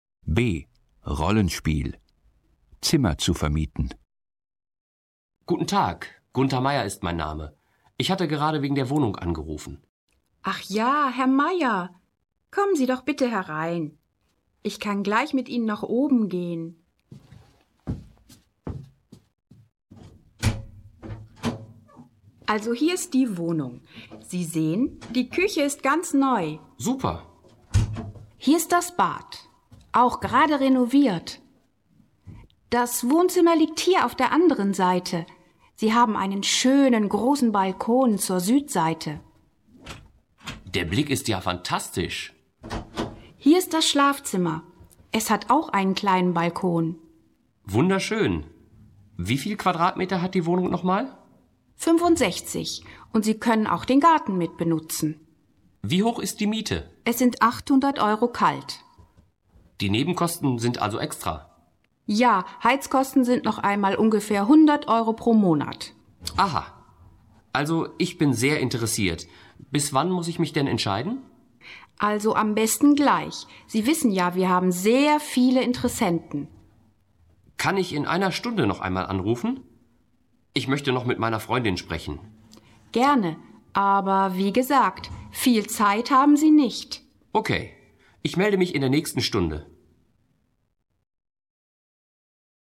Rollenspiel: Zimmer zu vermieten (1483.0K)